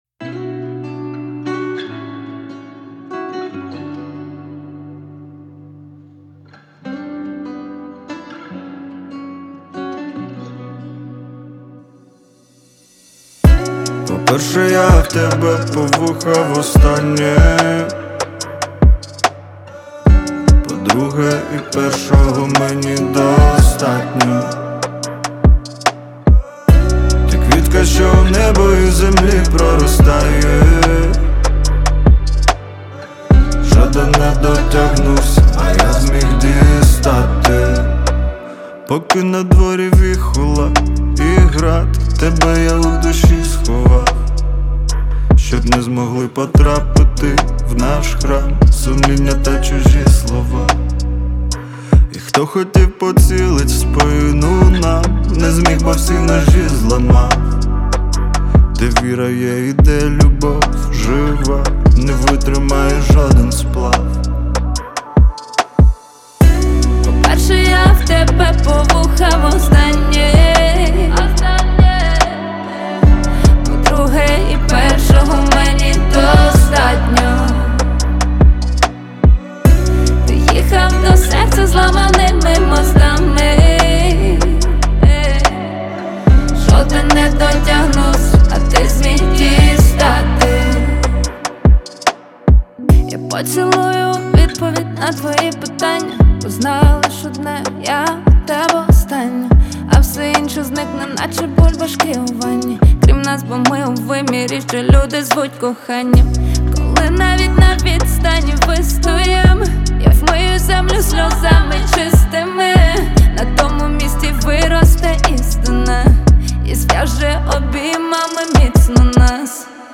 • Жанр: Українські пісні